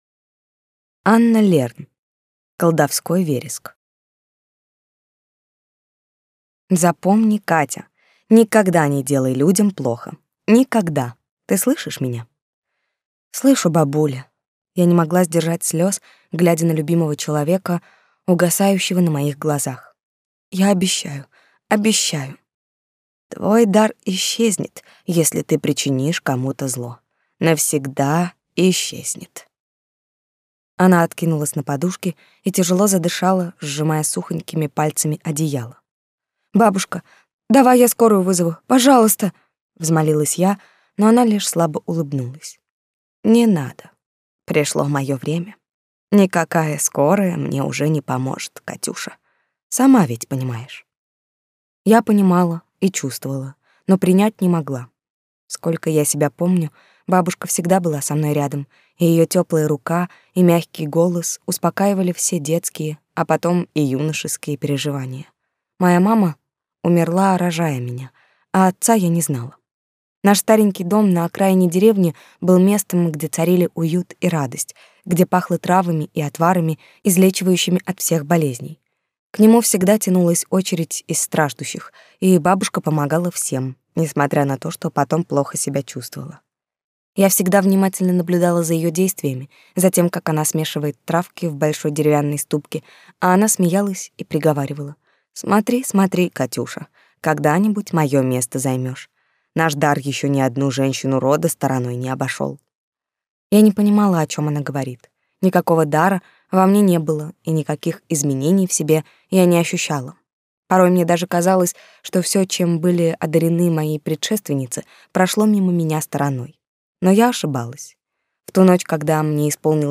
Аудиокнига Колдовской вереск | Библиотека аудиокниг
Прослушать и бесплатно скачать фрагмент аудиокниги